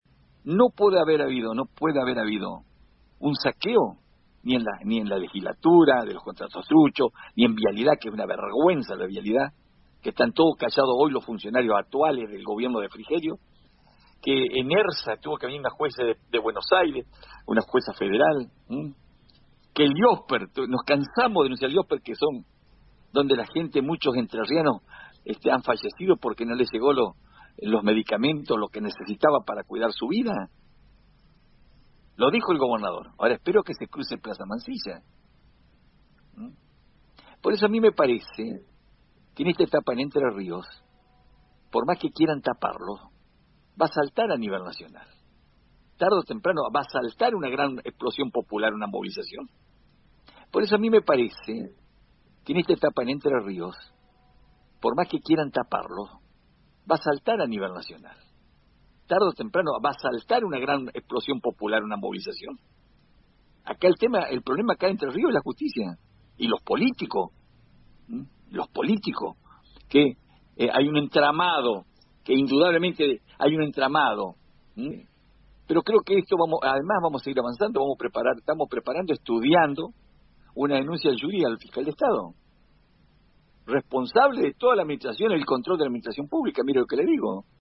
«El problema acá en Entre Ríos es la Justicia, y los políticos, que indudablemente hay un entramado. Pero vamos a seguir avanzando, estamos preparando una denuncia al Fiscal de Estado, responsable de toda la administración y el control de la administración pública», agregó el intendente de Santa Elena, Daniel Rossi, en diálogo con Radio RD 99.1